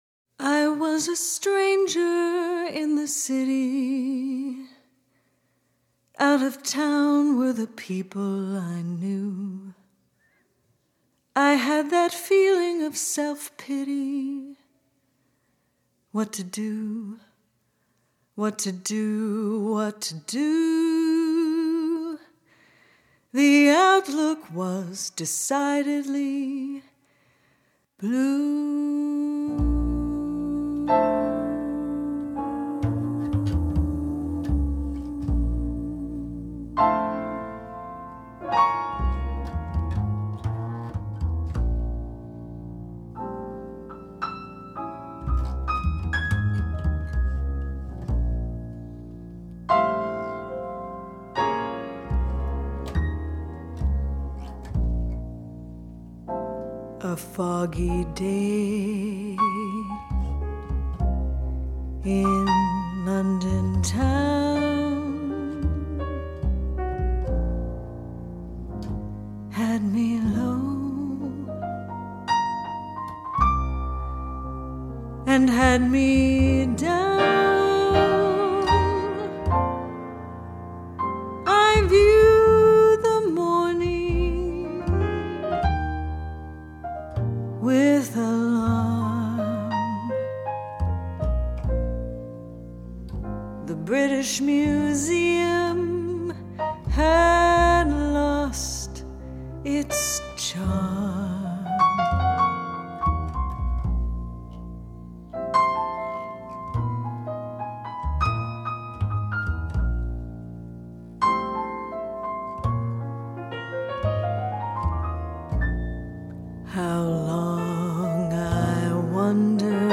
I am a singer.